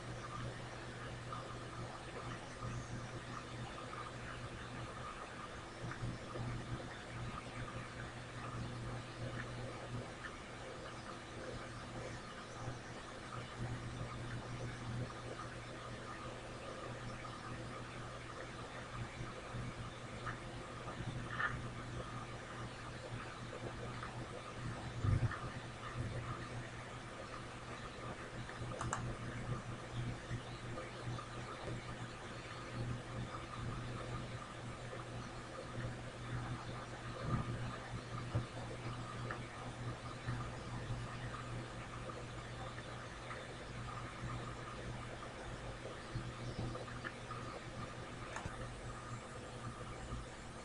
Below is a random ultrasound recording. No bats but you do get some chatter.
I transferred this file to audacity so I could do some editing to remove the noise and hear the chatter more clearly.
ultrasound chatter